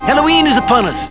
The Simpsons [Burns] Cartoon TV Show Sound Bites
burns_halloween.wav